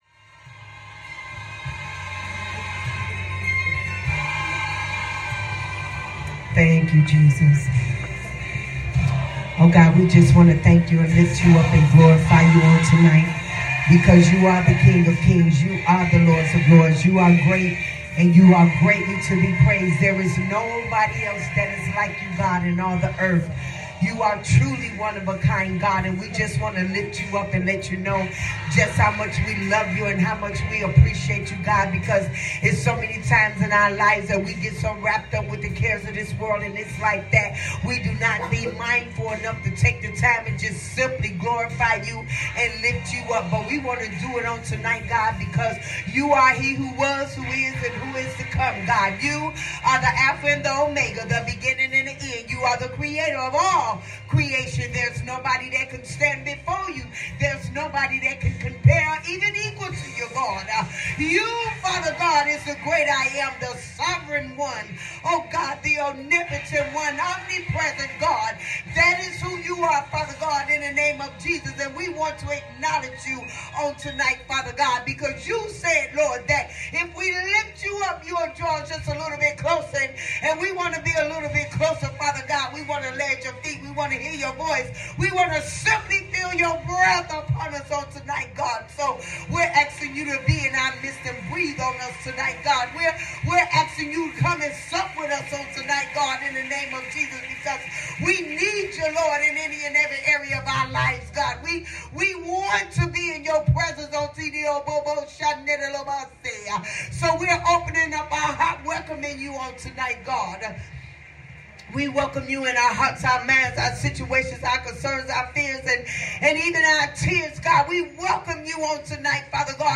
Breakthrough Service Prayer